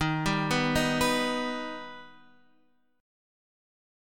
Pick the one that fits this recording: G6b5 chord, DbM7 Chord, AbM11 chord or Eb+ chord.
Eb+ chord